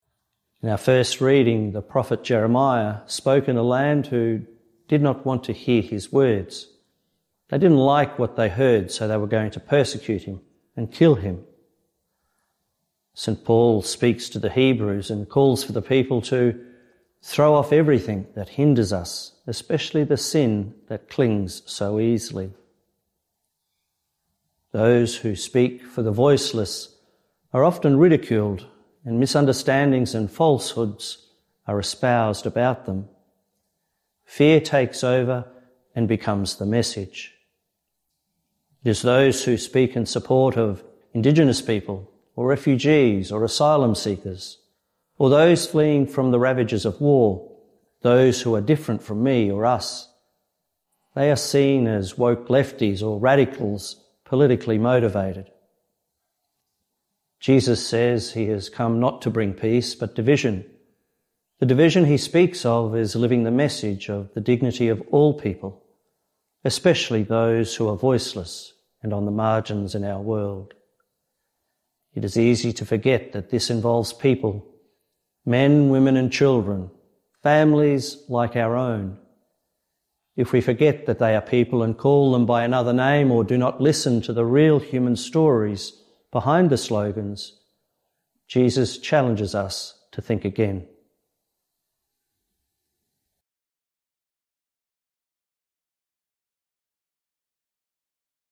Two-Minute Homily